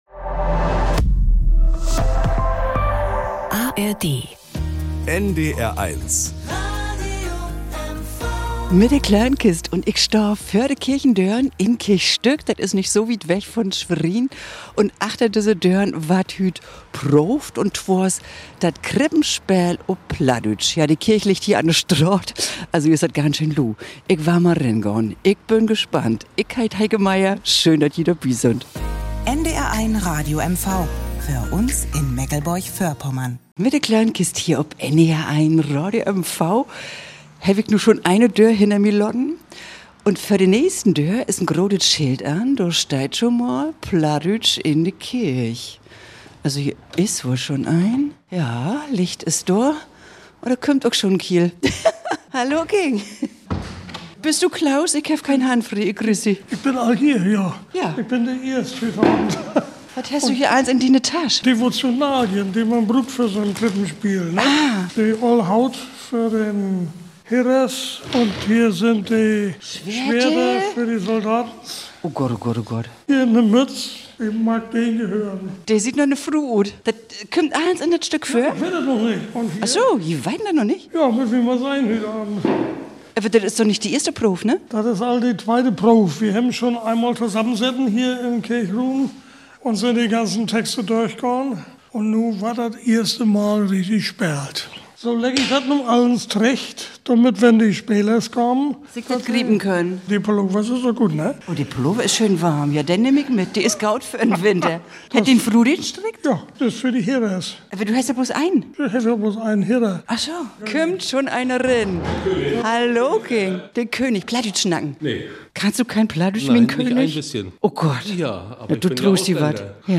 Was für ein Gewusel: der Schmied und die Wirtin, Josef und Maria und all die anderen kommen nach und nach zur zweiten Probe für das Krippenspiel in die kleine Kirche von Kirch Stück.